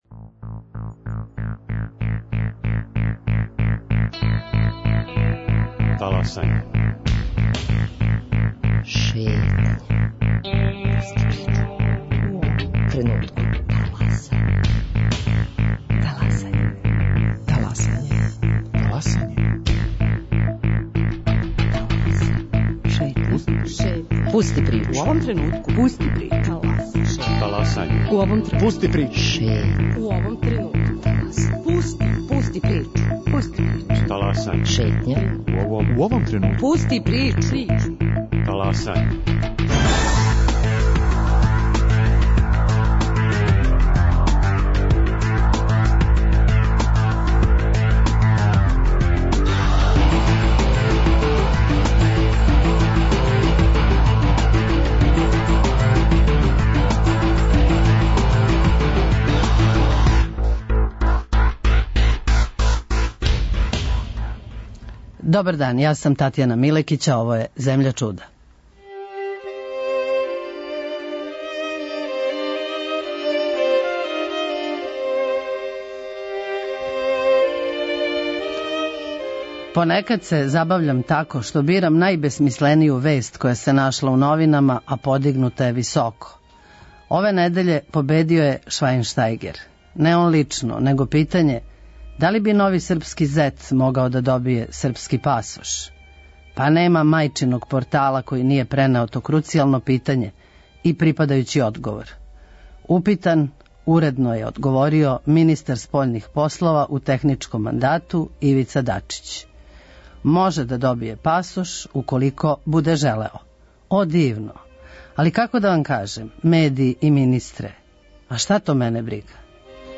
Из свечаних говора: „ ...тако почиње ход по мукама титана, човека који је пред собом и, неретко против себе имао васцели свет."